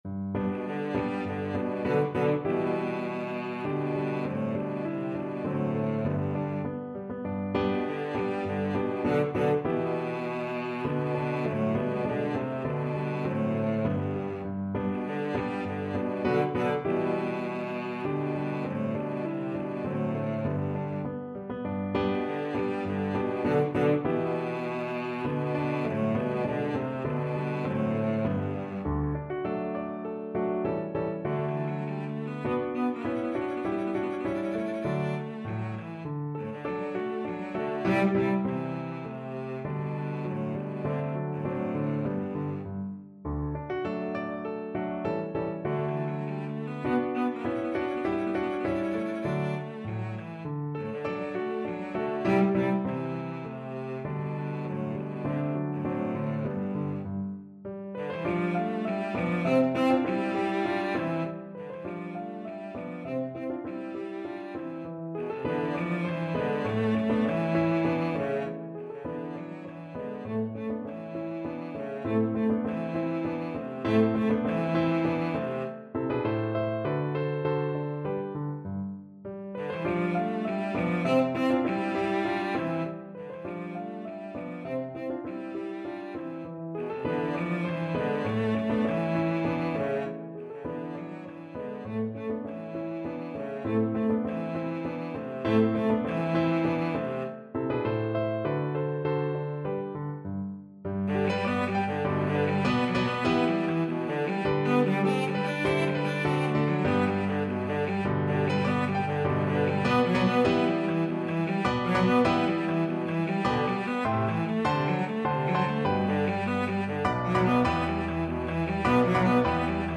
Cello version
3/4 (View more 3/4 Music)
Tempo di Menuetto
Classical (View more Classical Cello Music)